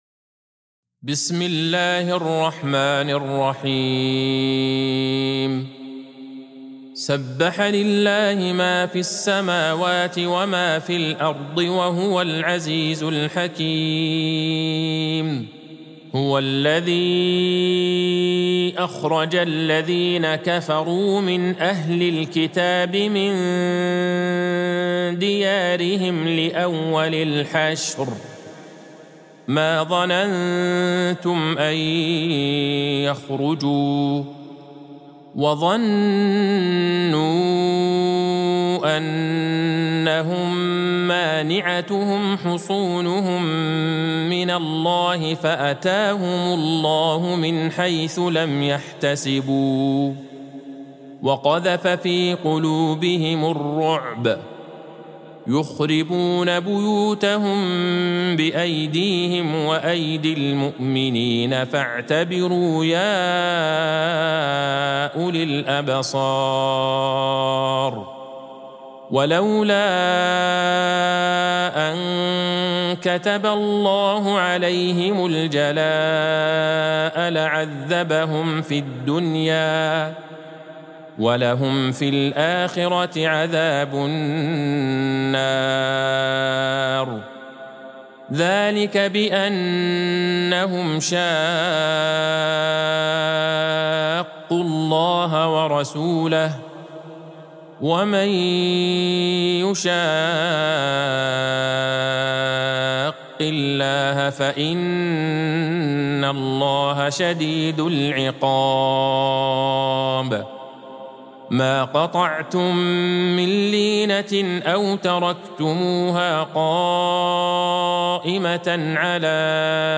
سورة الحشر Surat Al-Hashr | مصحف المقارئ القرآنية > الختمة المرتلة ( مصحف المقارئ القرآنية) للشيخ عبدالله البعيجان > المصحف - تلاوات الحرمين